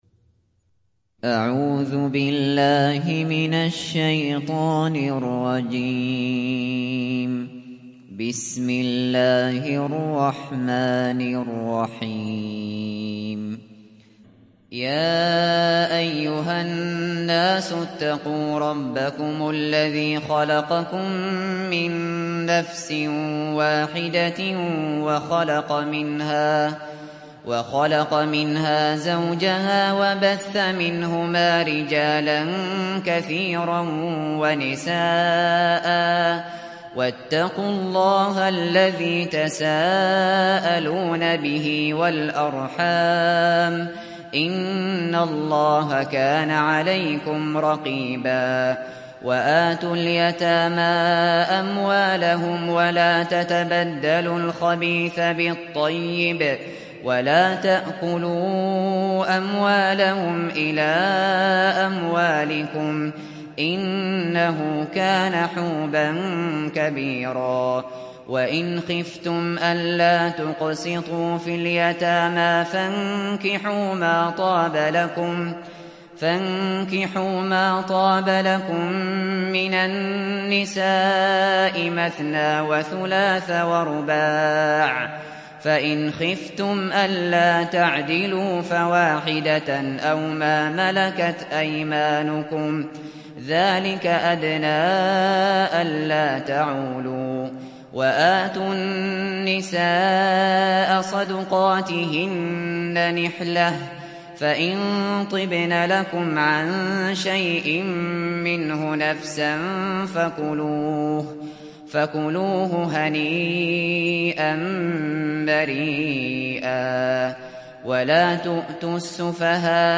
Surat Annisa Download mp3 Abu Bakr Al Shatri Riwayat Hafs dari Asim, Download Quran dan mendengarkan mp3 tautan langsung penuh